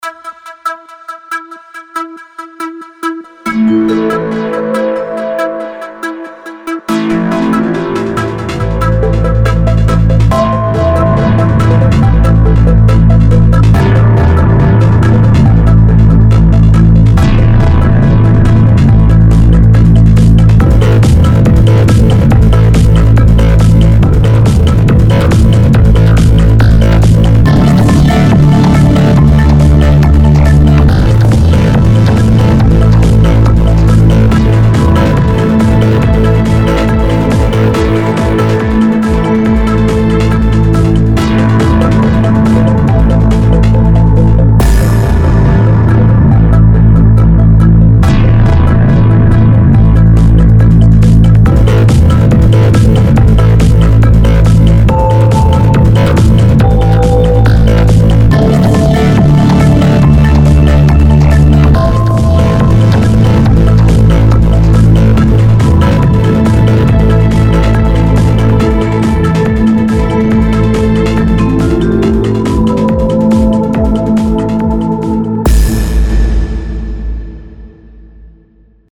Herzketten Versuch 1, SUNO AI, Modell V4 your_browser_is_not_able_to_play_this_audio Hat ein wenig asiatschen / japanischen klassichen Einschlag, vom Stil finde ich.
Wenn man dem System sagt, lass mal jemand weibliches Singen kommt was ganz anderes heraus Versuch 3 your_browser_is_not_able_to_play_this_audio Versuch 4 your_browser_is_not_able_to_play_this_audio Ich habe diesmal die Finger komplett vom Mixing gelassen.
Die Stimm-Synthese klingt nicht mehr ganz so zur Melodie gezogen. sondern schon fast natürlich.